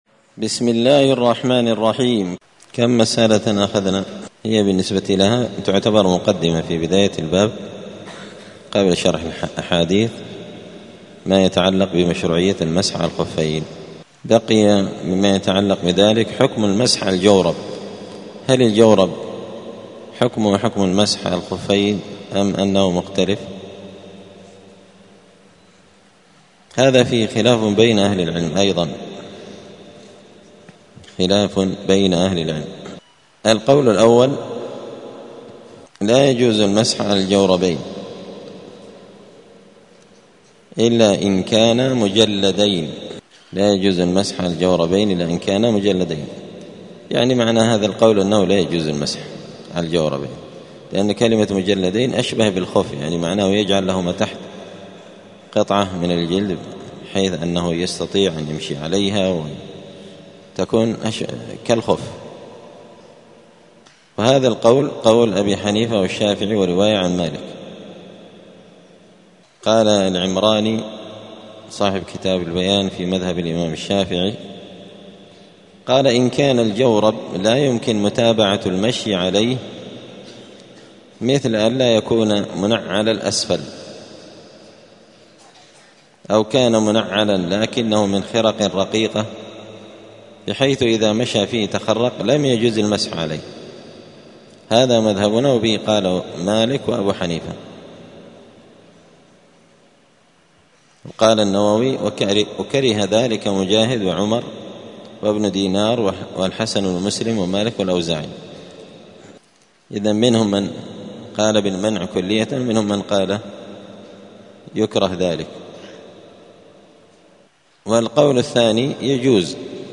دار الحديث السلفية بمسجد الفرقان قشن المهرة اليمن
*الدرس الثاني والأربعون [42] {باب صفة الوضوء حكم المسح على الجوربين}*